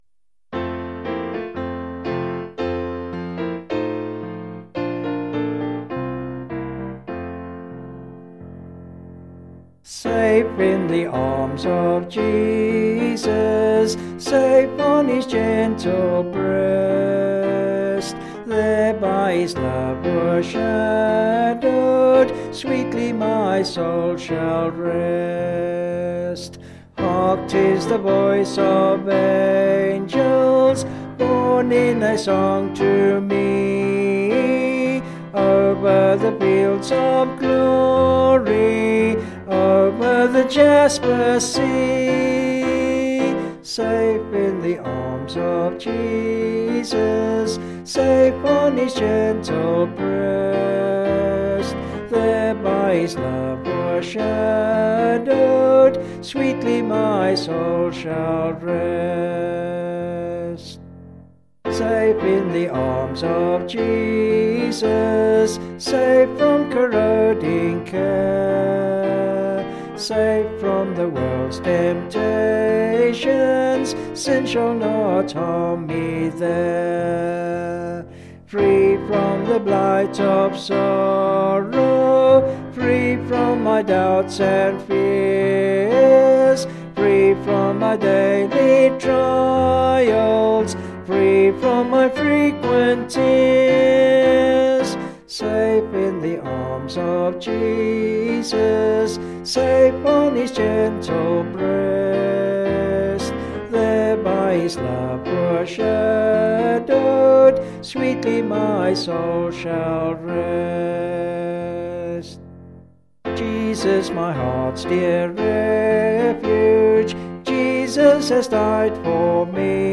Vocals and Piano   265.1kb Sung Lyrics